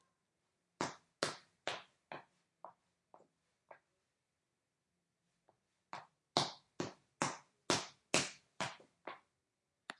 步伐 " 带高跟鞋的脚步声
描述： 有人穿着硬木和瓷砖走路，然后敲门。门打开了。
Tag: 高跟鞋 pasos WAV 普埃塔 tacones PISO-DE-马德拉